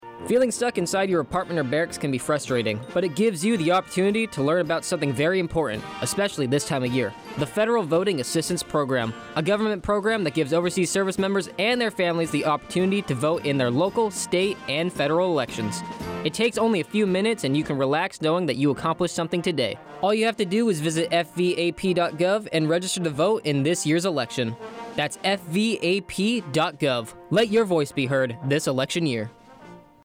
A spot for Radio to inform U.S. service members and U.S. citizens how they can participate in their local, state, and federal elections while overseas.